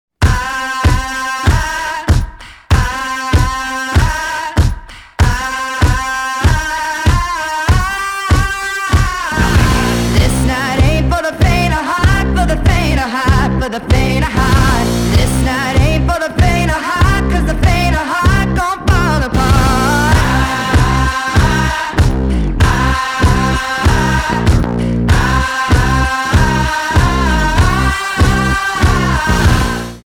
• Качество: 320, Stereo
ритмичные
Драйвовые
Hard rock
женский рок
arena rock